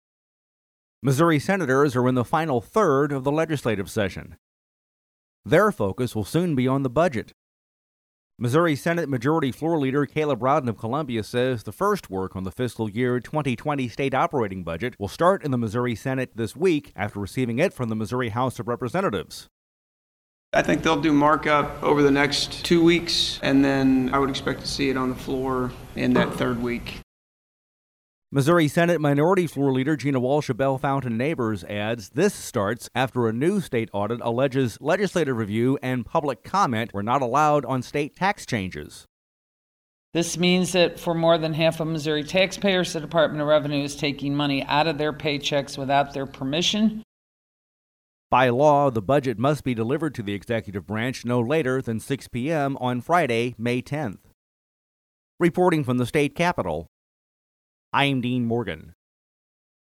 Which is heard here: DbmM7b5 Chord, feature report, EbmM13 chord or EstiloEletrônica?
feature report